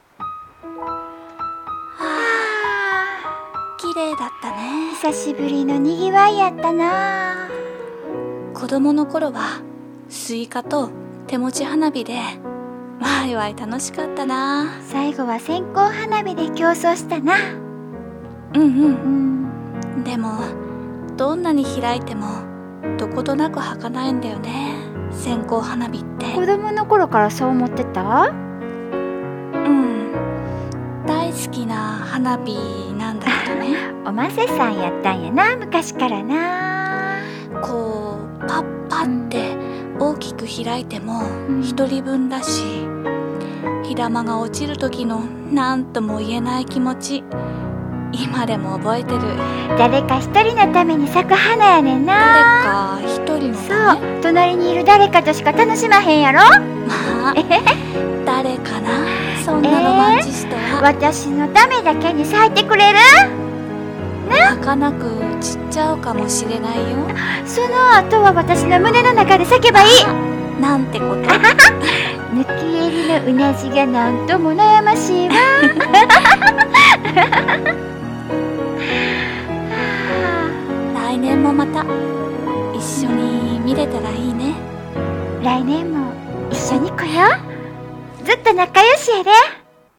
【声劇】線香花火